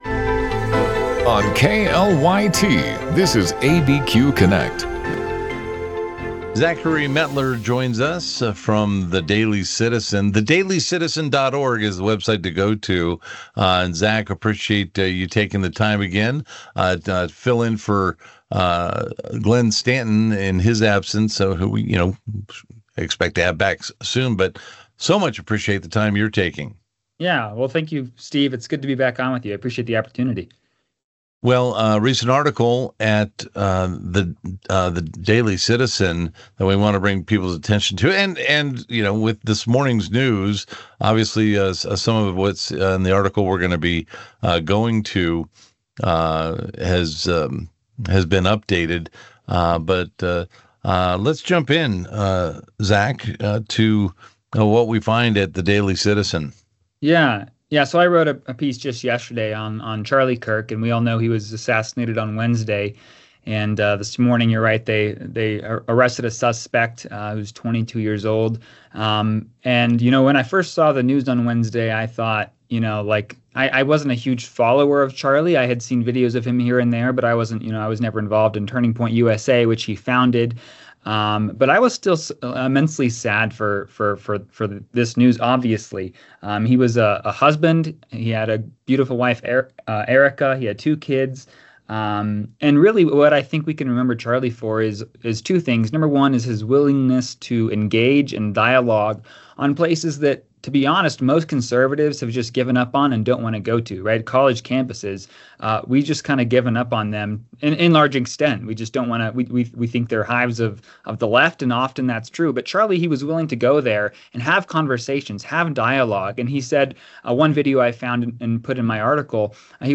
Live, local and focused on issues that affect those in the New Mexico area.